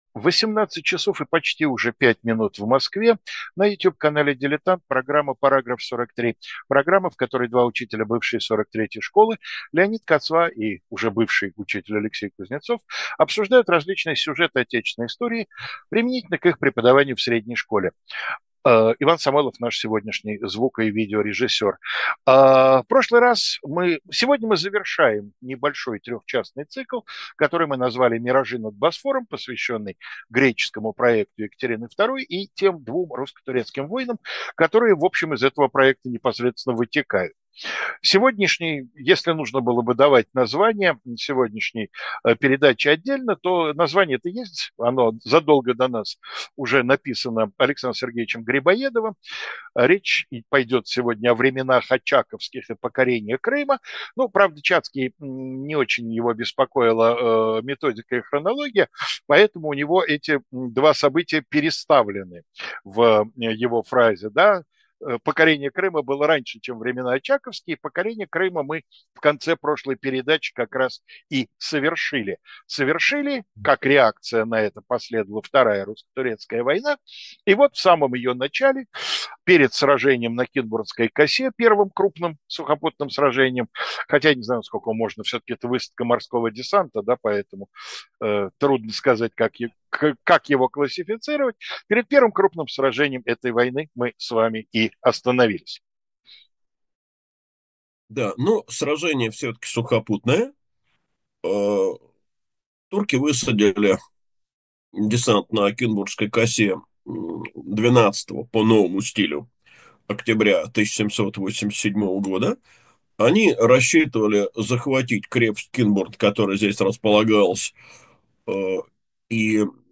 Программа, в которой два историка рассуждают о том, как различные сюжеты истории могли бы преподаваться в школе